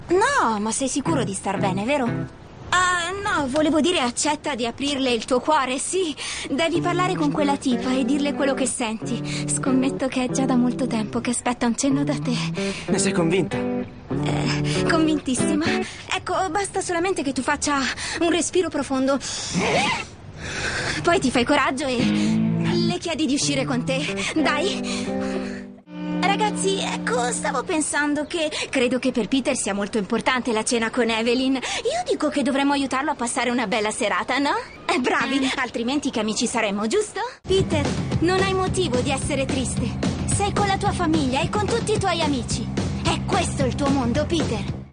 nel telefilm "Peter Punk", in cui doppia Lucěa Precul.